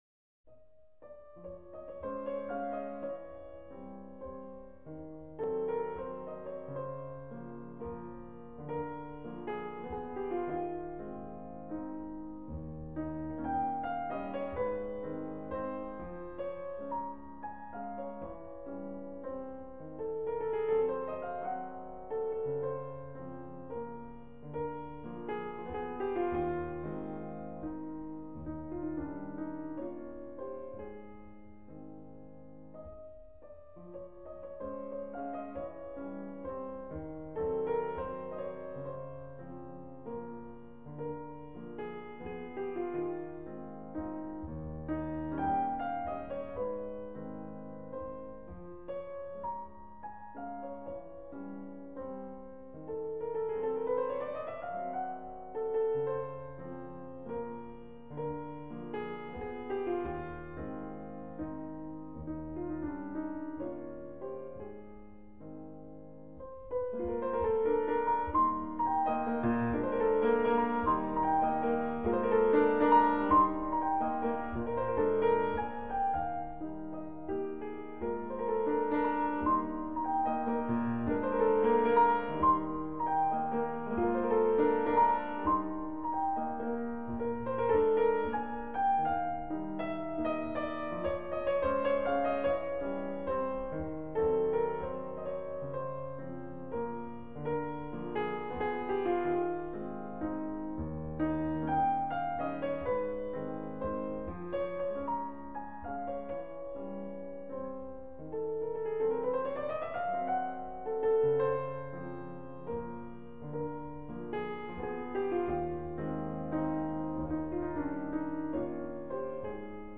物憂げな旋律が印象的です。